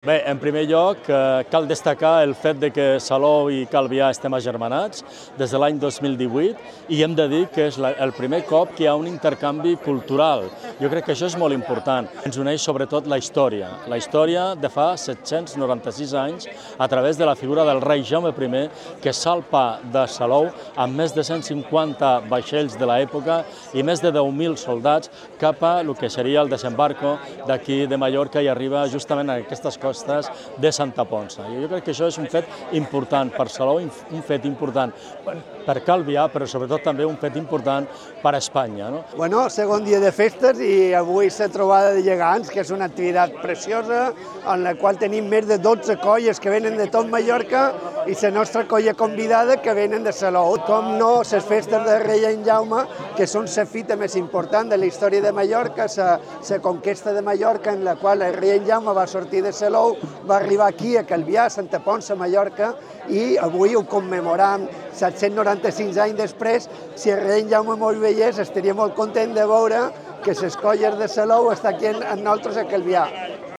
mp3-mayors-of-salou-and-calvia.mp3